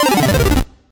line_complete.ogg